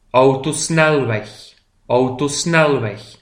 PRONONCIATION :